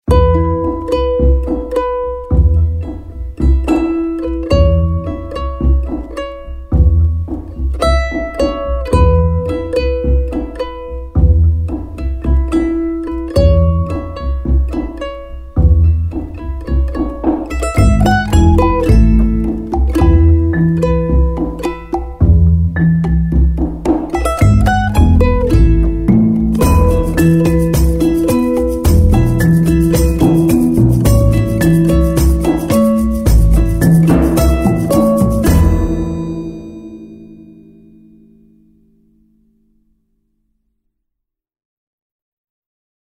Largo [40-50] amour - accordeon - - -